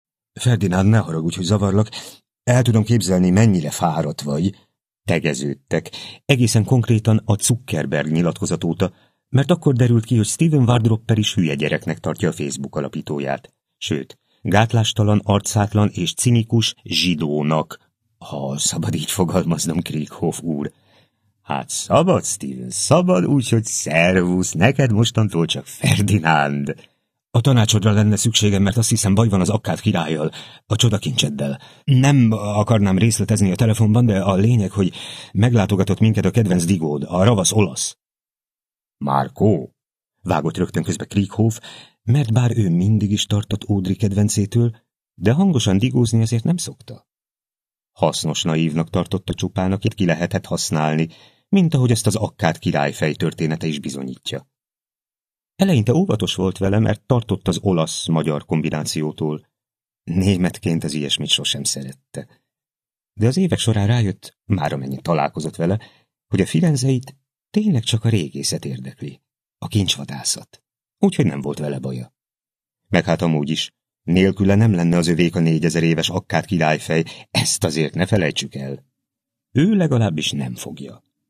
Bábel (Online hangoskönyv) Viczián Ottó előadásában Frei Tamás Alexandra Kiadó (A) Online hangoskönyv / Regény / Thriller Hallgass bele!
A hangoskönyv Viczián Ottó előadásában hallható.